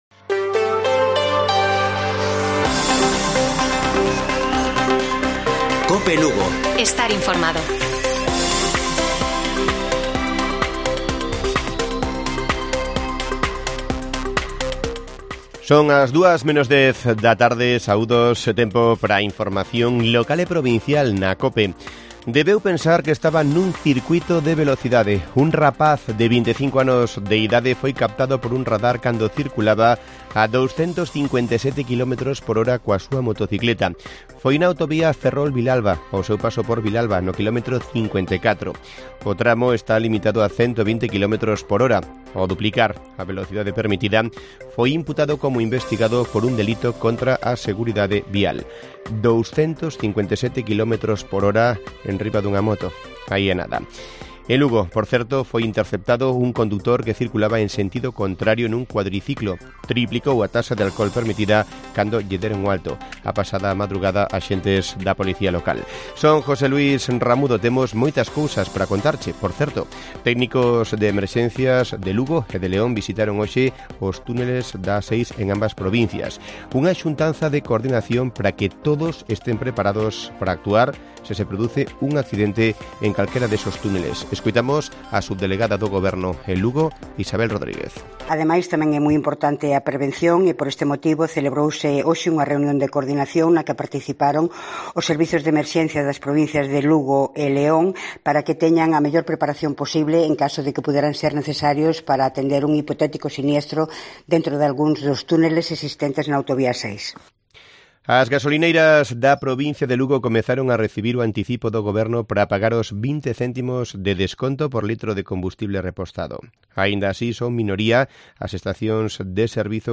Informativo Mediodía de Cope Lugo. 06 de abril. 13:50 horas